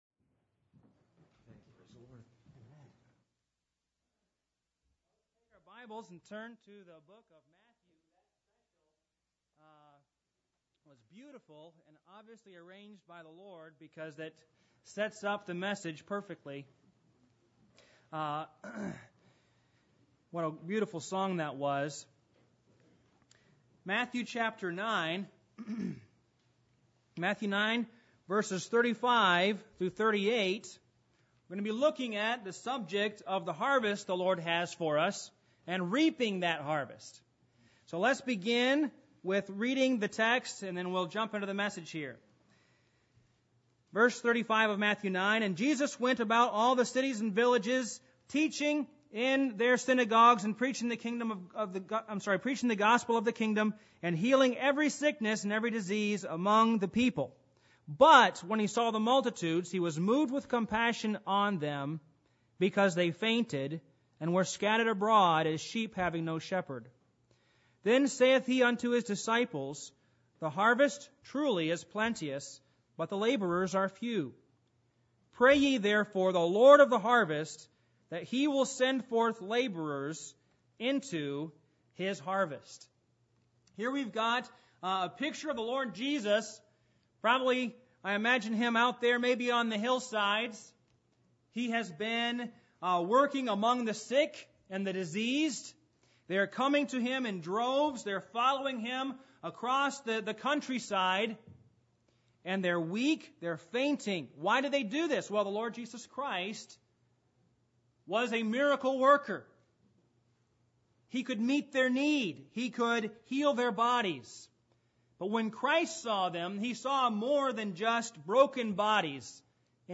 Passage: Matthew 9:36-38 Service Type: Missions Conference %todo_render% « The Work of Jesus Christ Daniel